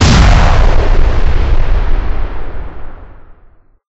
* Adds more explosion sound effects
explosion2.ogg